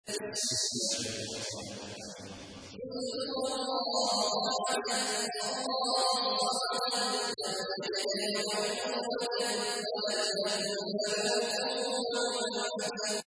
تحميل : 112. سورة الإخلاص / القارئ عبد الله عواد الجهني / القرآن الكريم / موقع يا حسين